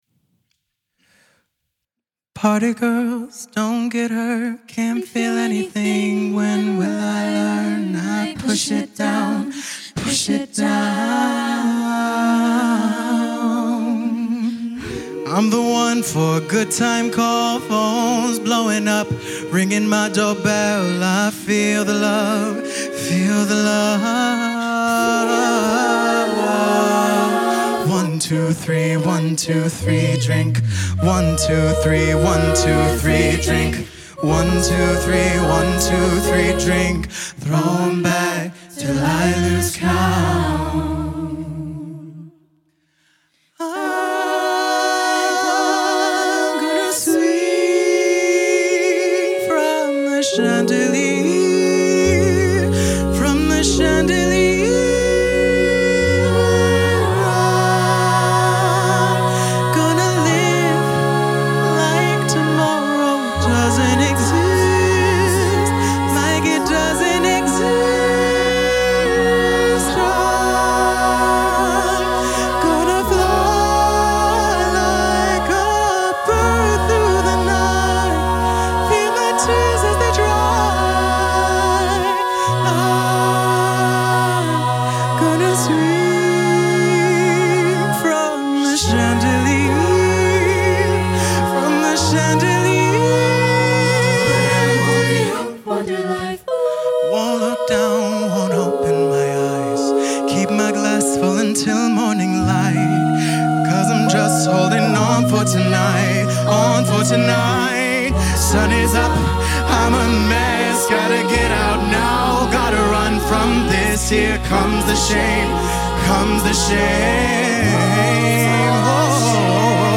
With 17 voices, it was going to take 17 wireless mics.
Beatbox mic not pictured
But in place of more photos, I do have a mix of one song!